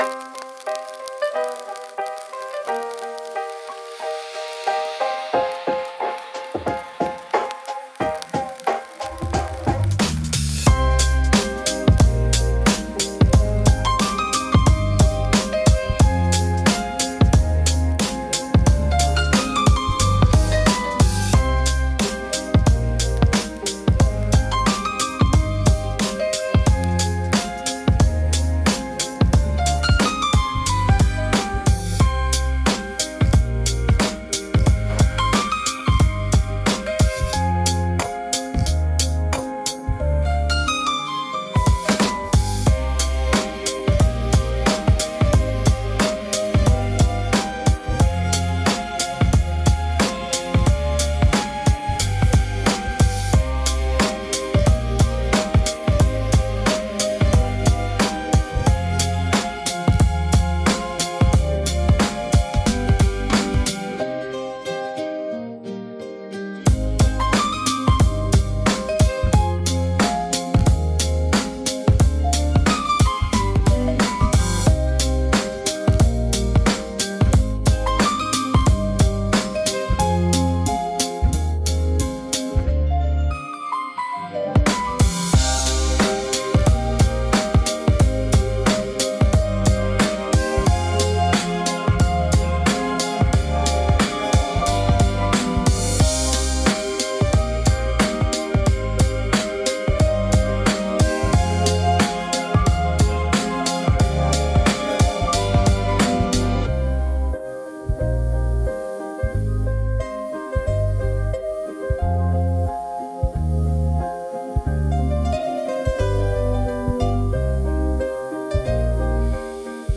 inspirations 90 BPM – Do mineur